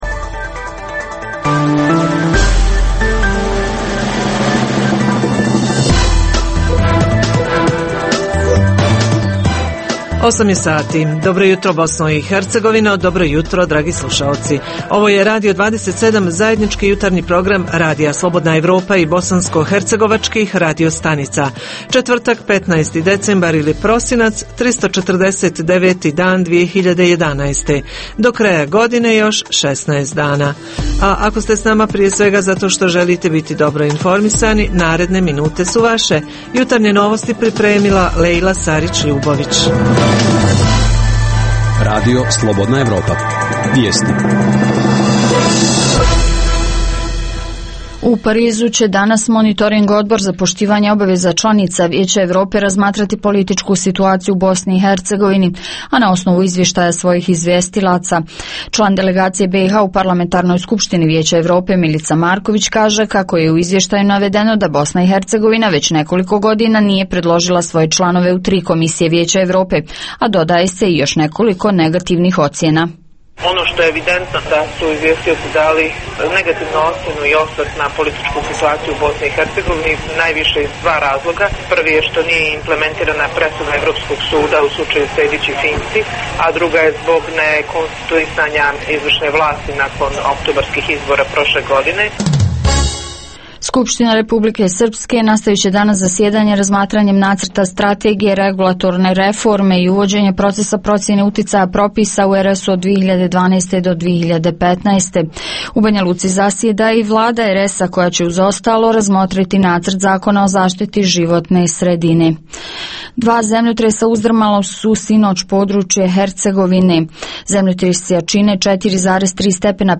Reporteri Jutarnjeg programa iz više bh. gradova govore i o tome kakva je situacija u njihovim sredinama, koliko su zadovoljni vaspitači, a koliko djeca i njihovi roditelji.
- Redovni sadržaji Jutarnjeg programa za BiH su i vijesti i muzika.